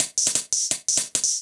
UHH_ElectroHatD_170-03.wav